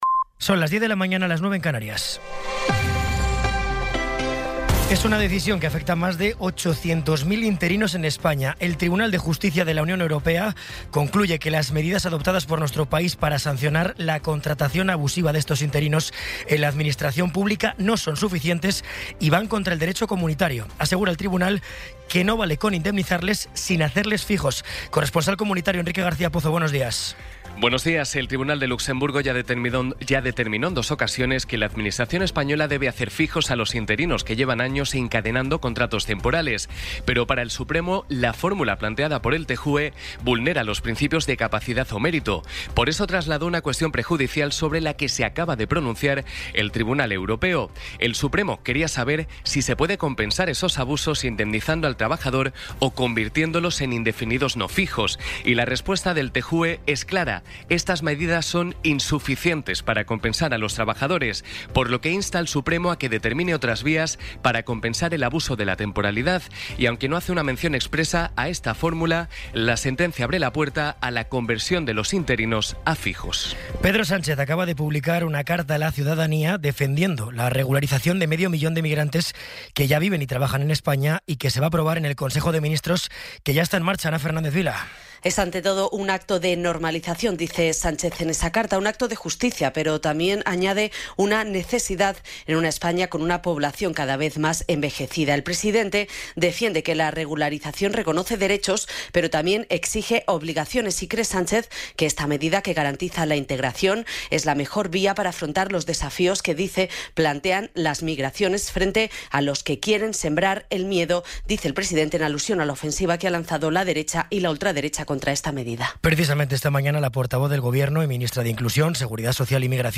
Resumen informativo con las noticias más destacadas del 14 de abril de 2026 a las diez de la mañana.